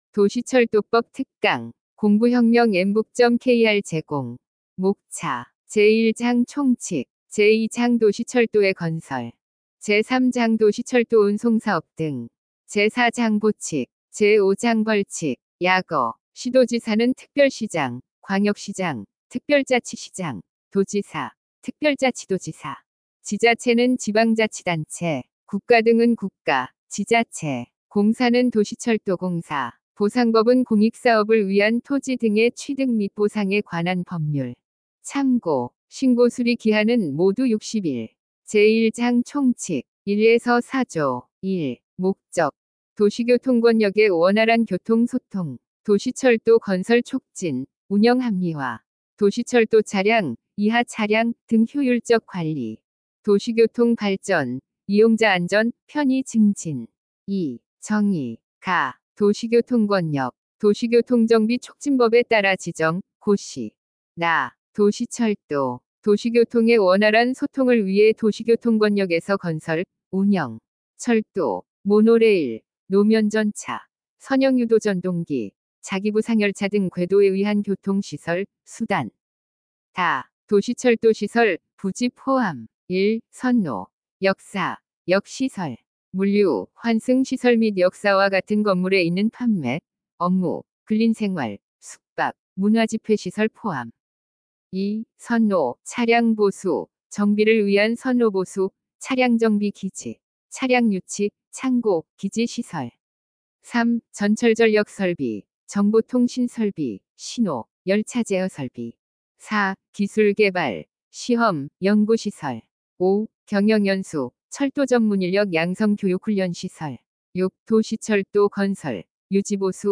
엠북학원은 우리나라 최초의 인공지능이 강의하는 사이버학원이며, 2025년 4월 28일 개원하였습니다.
도시철도법-특강-샘플.mp3